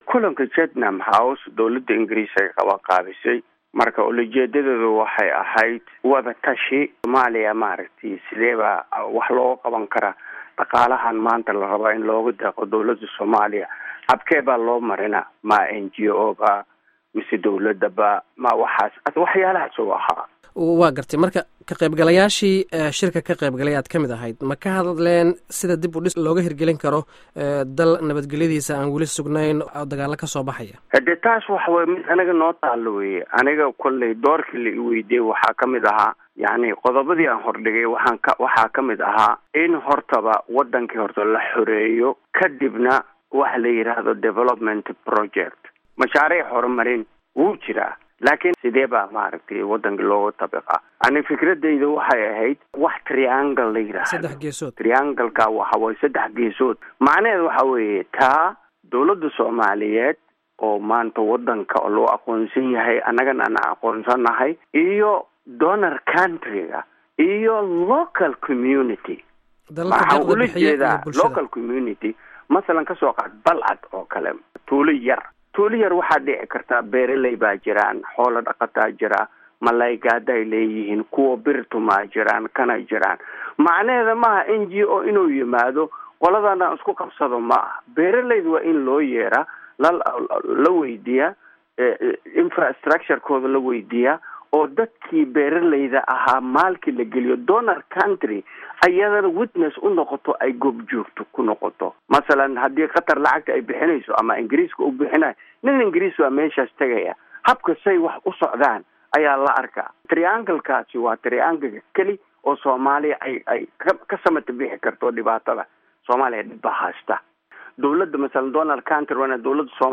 Wareysiga shirka Chatham House, London